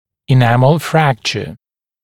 [ɪ’næml ‘frækʧə] [и’нэмл ‘фрэкчэ] повреждение, скол эмали